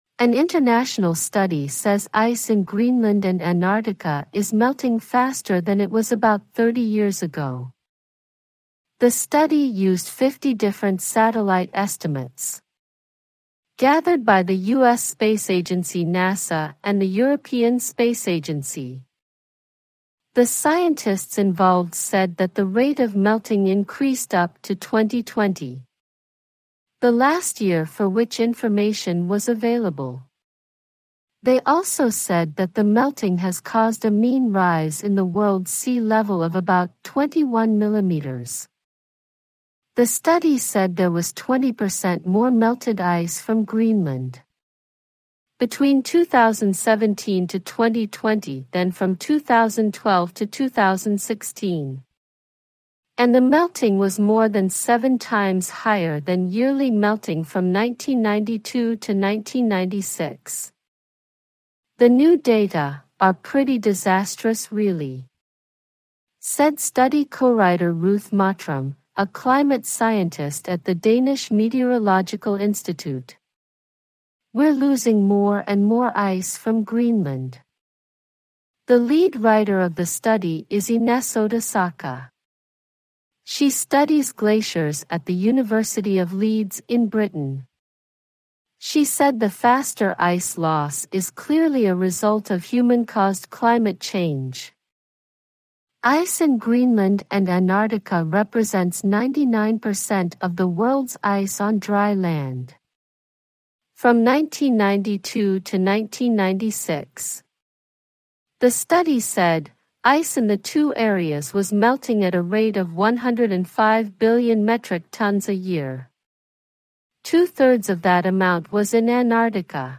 The story is read one-third slower than normal speaking speed using an artificial intelligence voice generator.